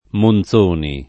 [ mon Z1 ni ]